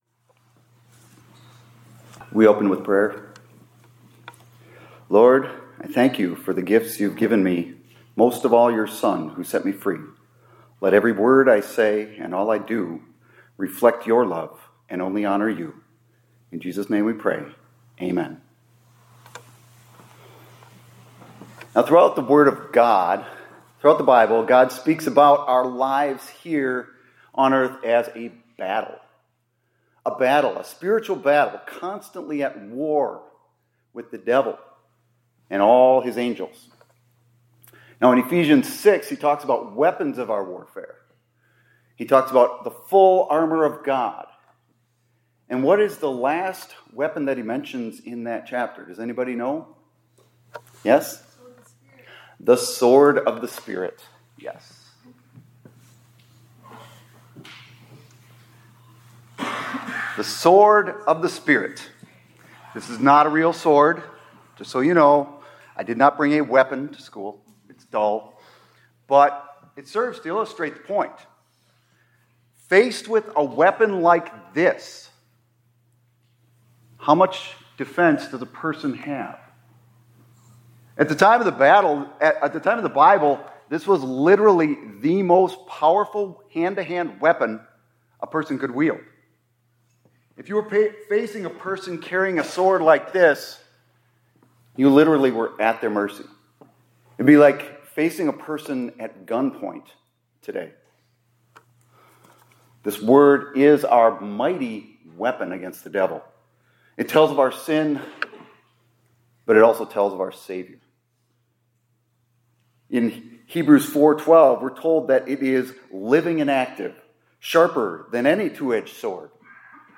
2026 Chapels -
Hymn: WS 768, st. 1,5 : Be Strong in the Lord